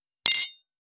331ガラスのグラス,ウイスキー,コップ,食器,テーブル,チーン,カラン,キン,コーン,チリリン,カチン,チャリーン,クラン,カチャン,クリン,シャリン,チキン,コチン,カチコチ,チリチリ,シャキン,
コップワイン効果音厨房/台所/レストラン/kitchen室内食器